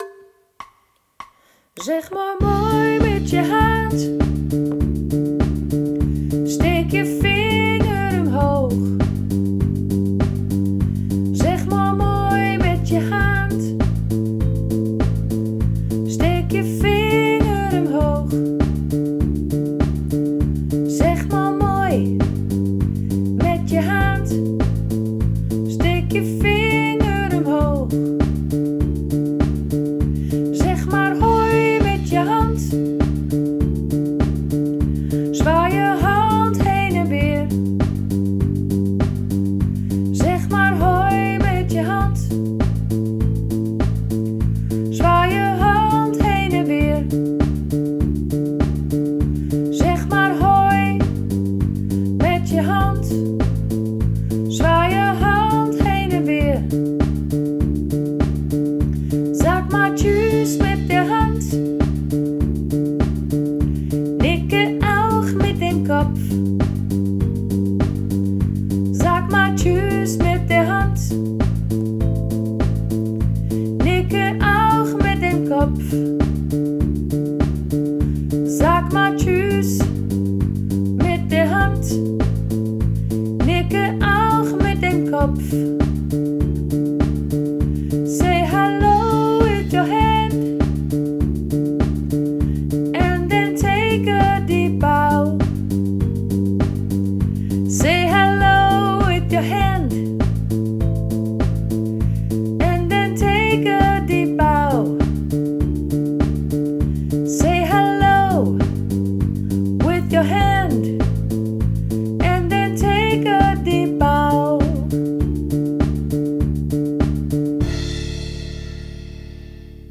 Download Zeg Maor Moi (gezongen in toonsoort Dm)